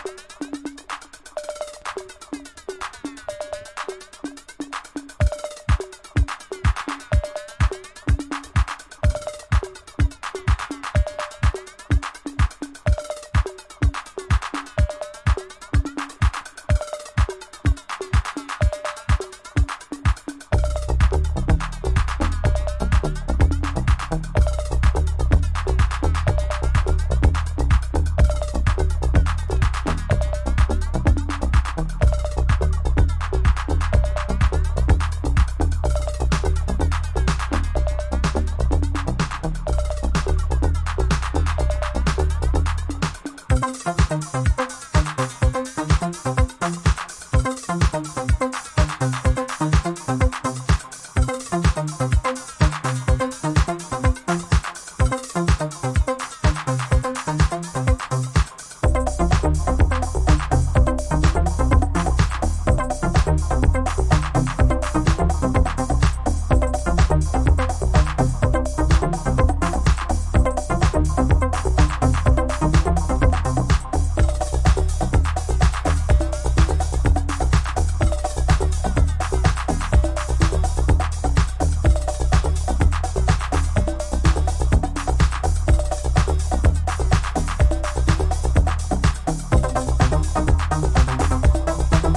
あらゆるヴィンテージ機材を駆使して、80年代後期のシカゴハウスにアプローチ。